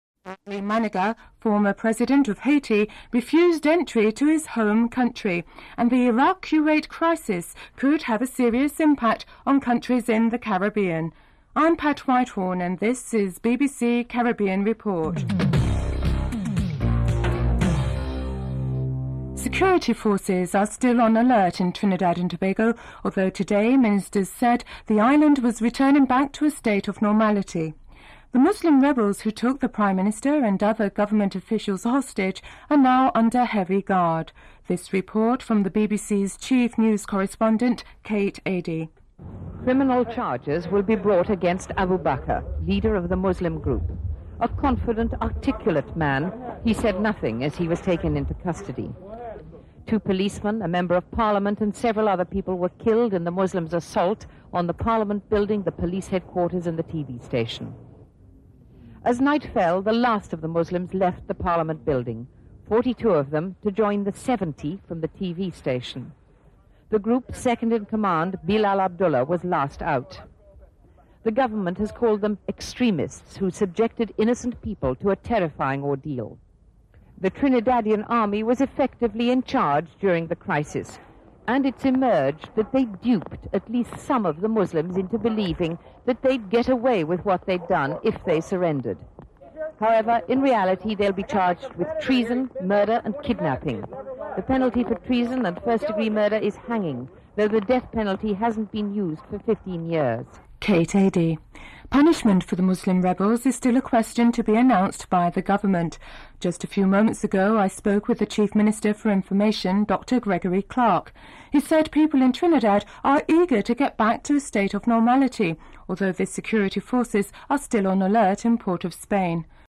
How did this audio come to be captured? Report commences during the headline segment.